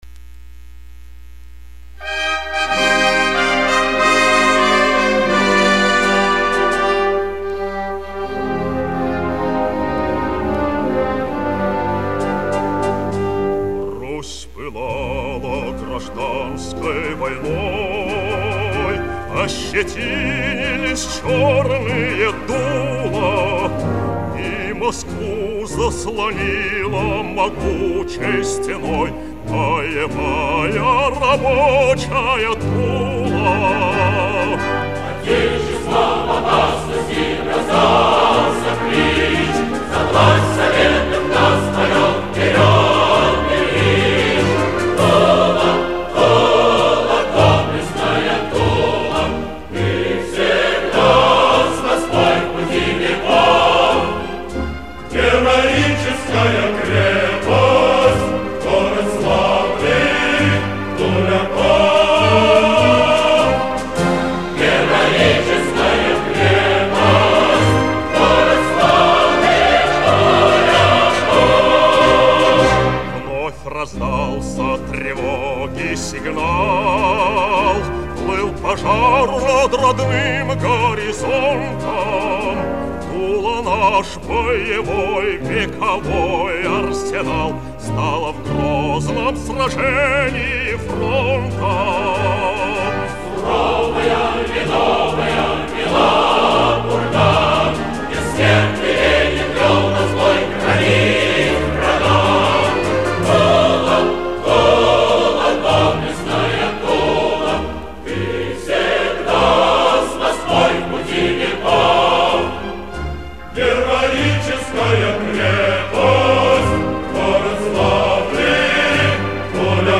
Мощная монументальная песня-баллада о Туле